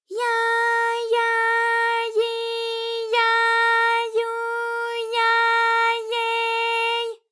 ALYS-DB-001-JPN - First Japanese UTAU vocal library of ALYS.
ya_ya_yi_ya_yu_ya_ye_y.wav